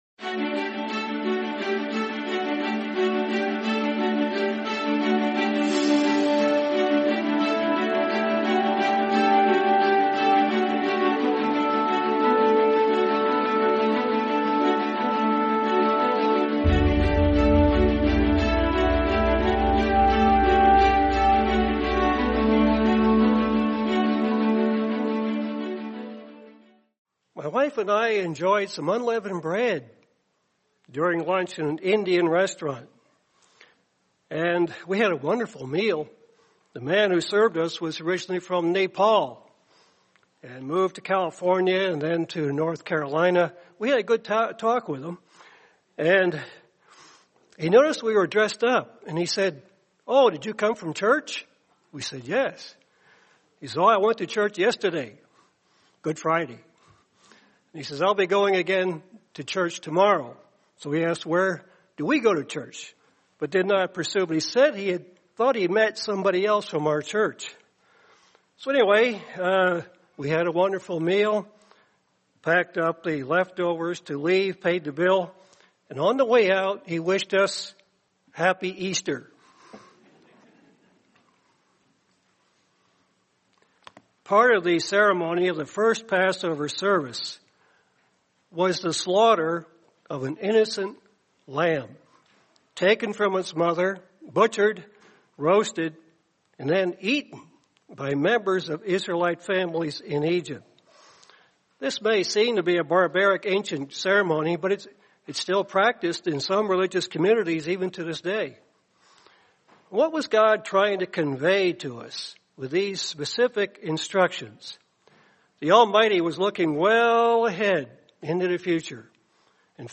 Sermon The Enthroned Lamb